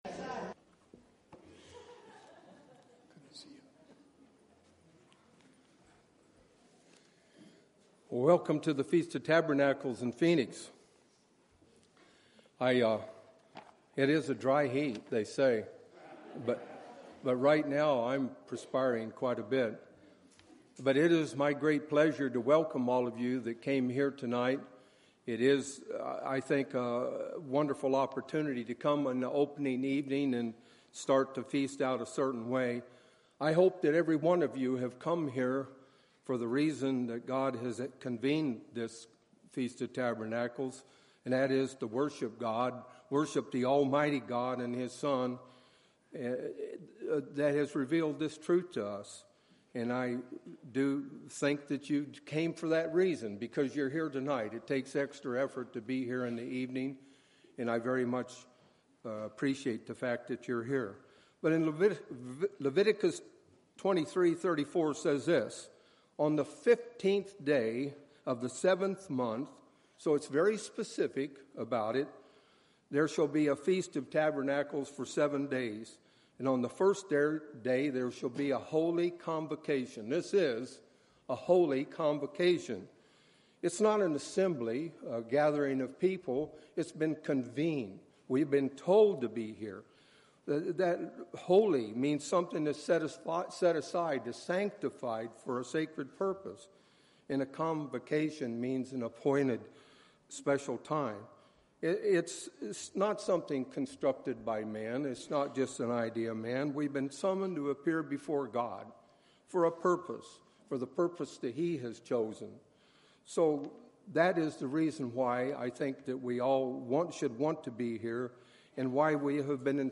This sermon was given at the Phoenix, Arizona 2017 Feast site.